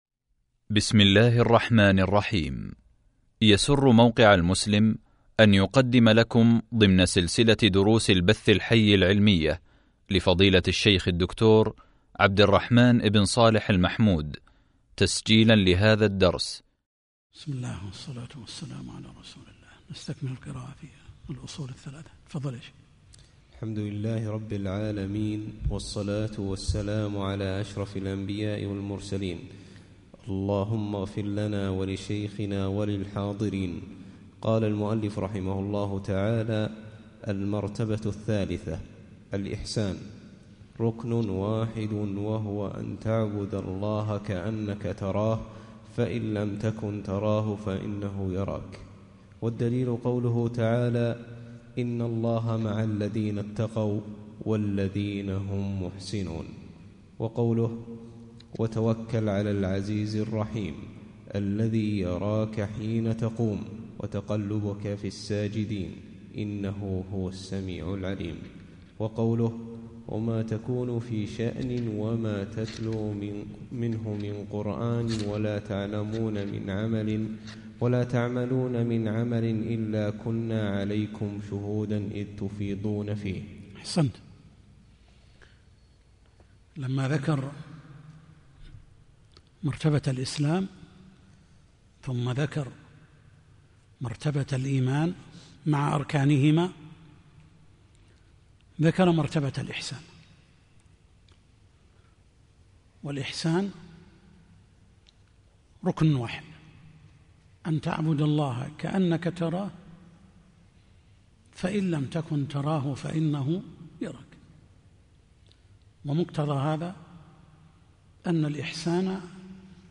شرح الأصول الثلاثة | الدرس 18 | موقع المسلم